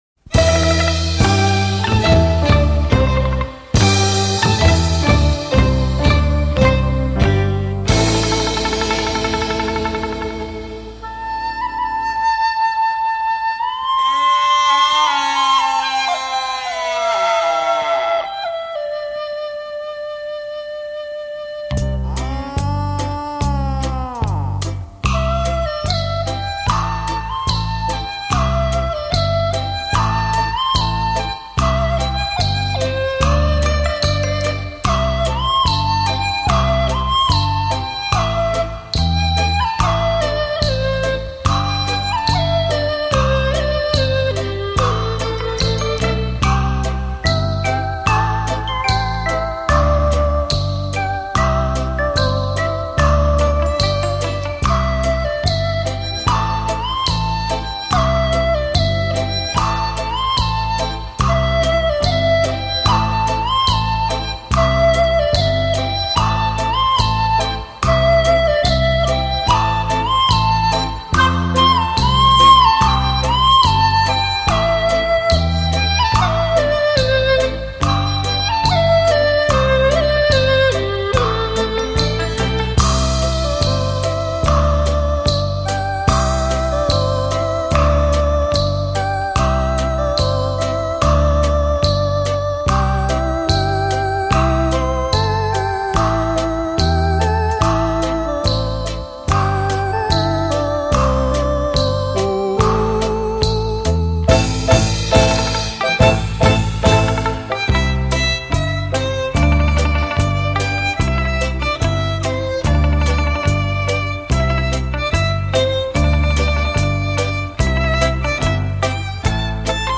柔美恒久的音乐，带给您昔日美好回忆